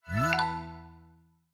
ChargingStarted.ogg